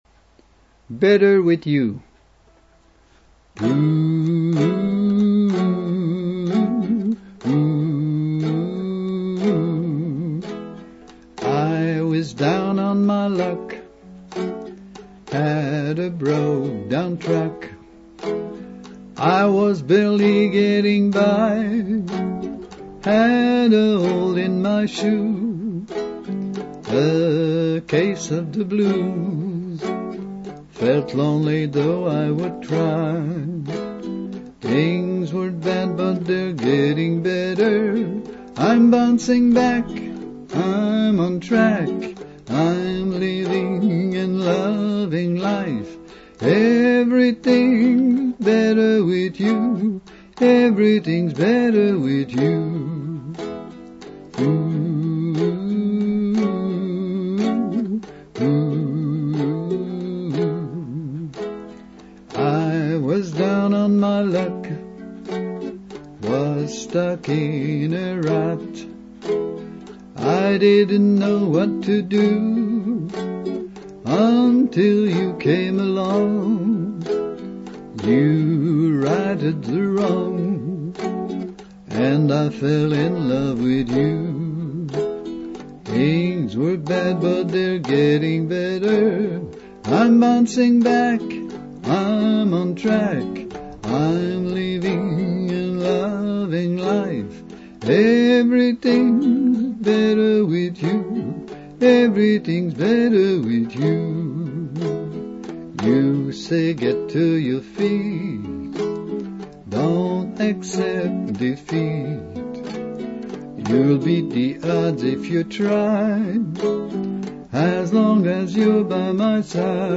betterwithyouuke.mp3
key of E, 6/8